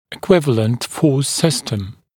[ɪ’kwɪvələnt fɔːs ‘sɪstəm][и’куивэлэнт фо:с ‘систэм]эквивалентная система сил